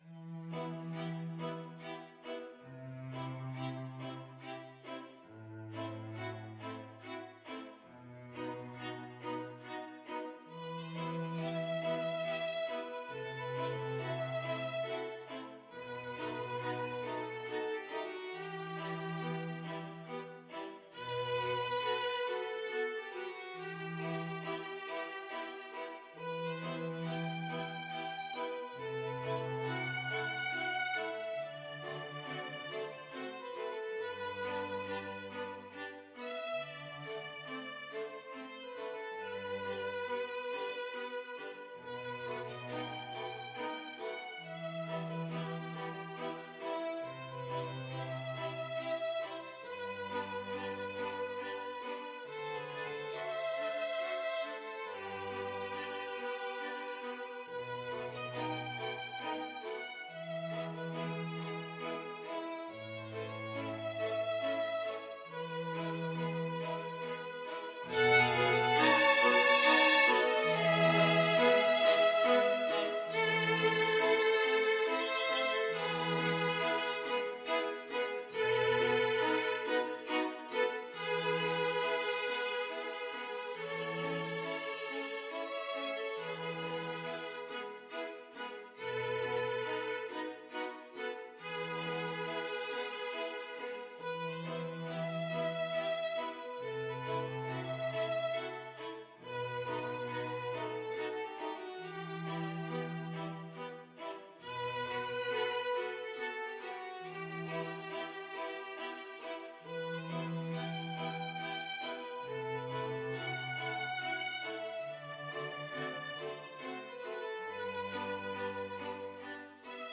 Schubert, Franz - Serenade (Standchen)(Sharp Key Version) Free Sheet music for Flexible Mixed Ensemble - 5 Players
Classical